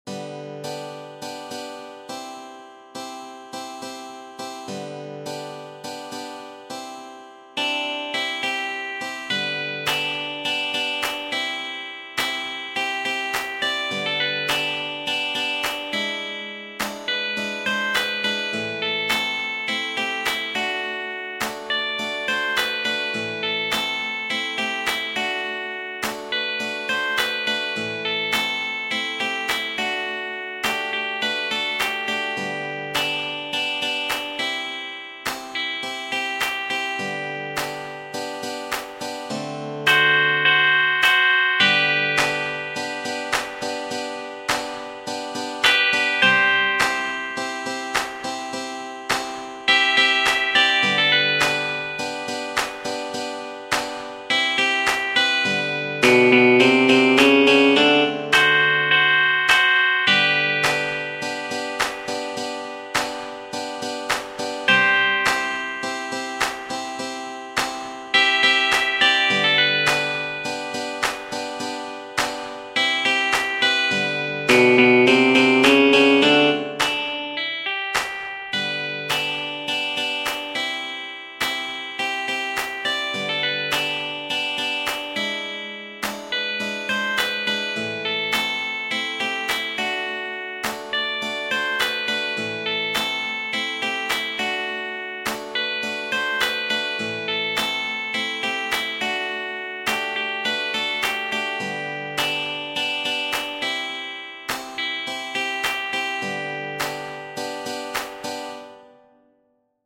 Genere: Religiose